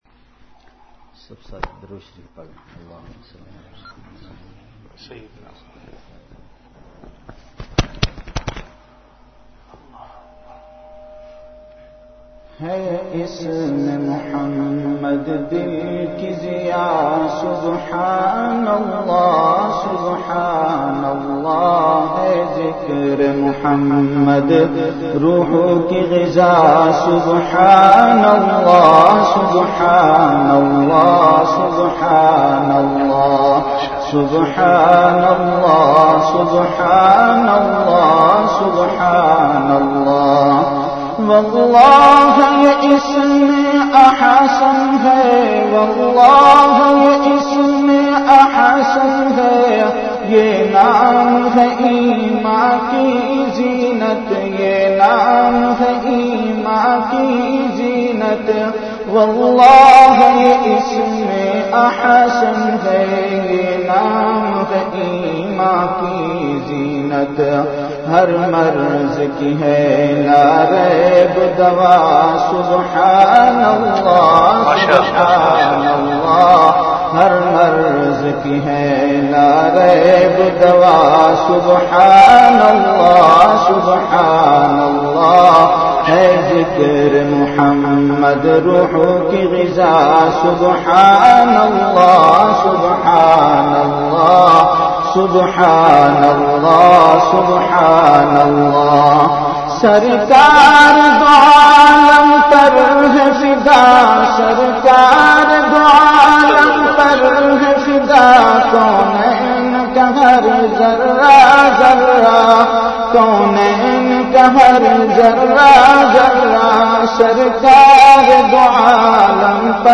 Delivered at Home.
Venue Home Event / Time After Isha Prayer